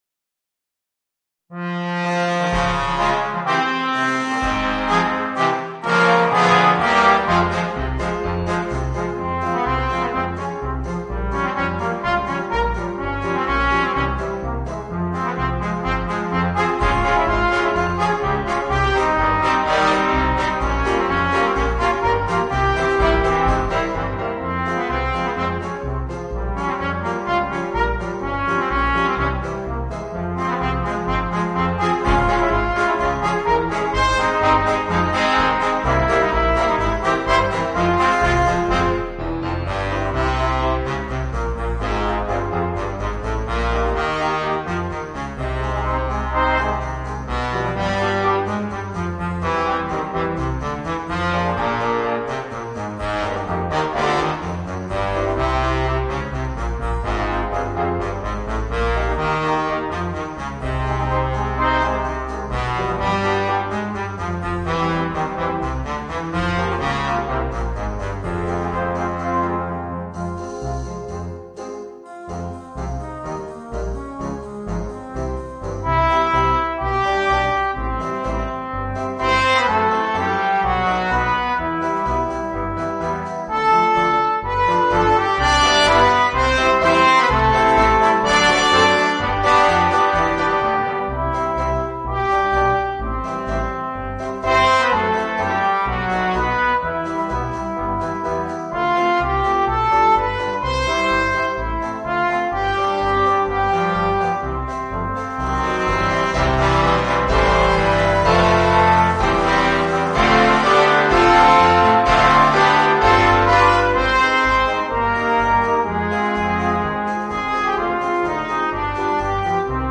Voicing: 4 Trombones